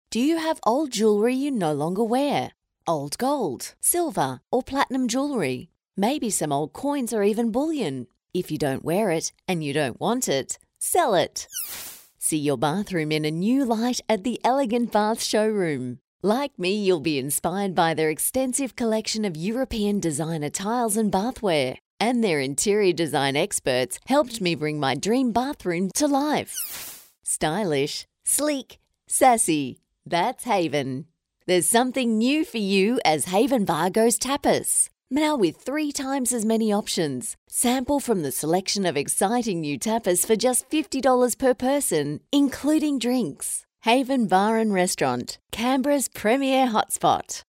I’m an authentic Australian female voiceover artist with over 18 years of experience.
I can provide a quality studio recording from my home studio with a Rode NT1-A microphone and Adobe Audition editing software.
• Retail Friendly